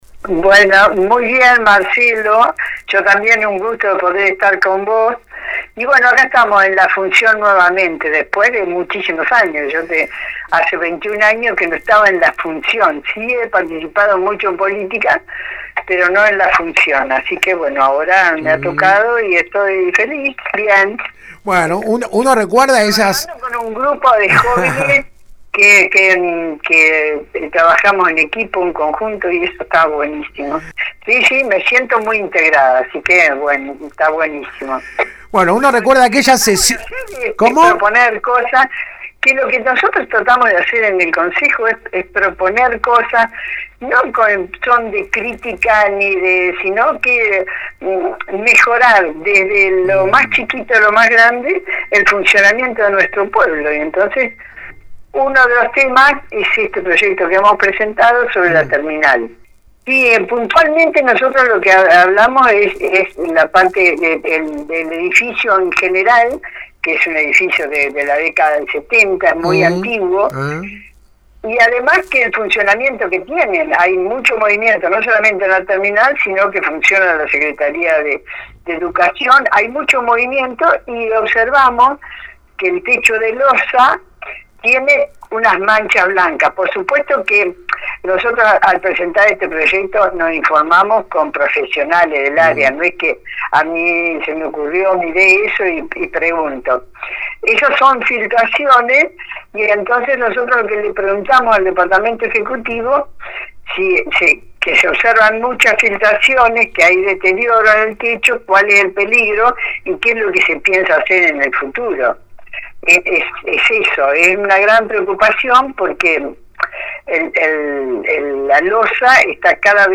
Anticipando algunos de los temas que se tratarán este jueves en una nueva sesión ordinaria del HCD local hablamos esta mañana con la concejal por el Bloque Adelante-Juntos.